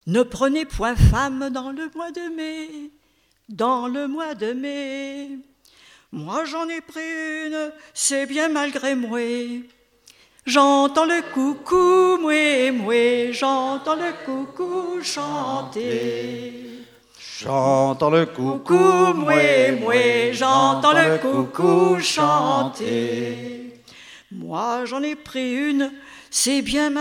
Festival du chant traditionnel
Pièce musicale inédite